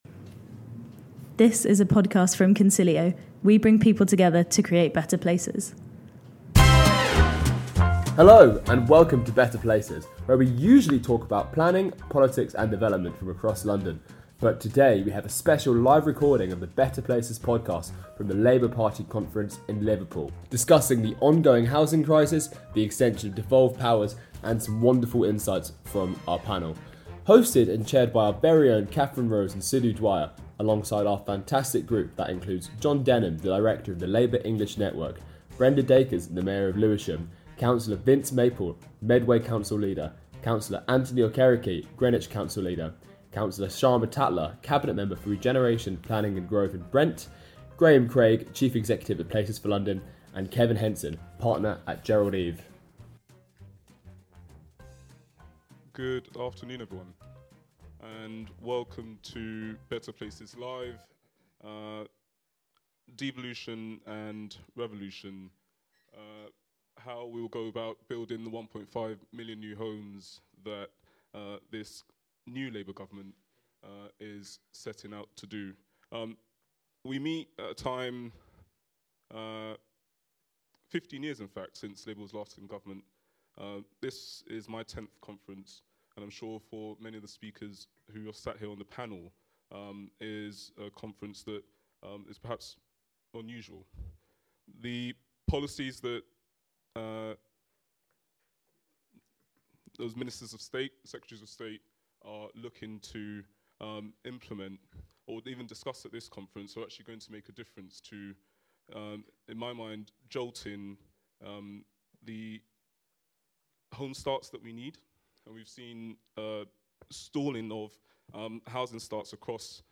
hosted a live recording of our Better Places Podcast at Labour Party Conference in Liverpool. The panel explored the ways in which English devolution can be delivered in support of the new Labour government’s mission to build 1.5million new homes, how to deliver the required infrastructure to support this and how to bring local communities along through effective engagement.